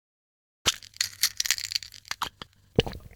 rogue_medkit.ogg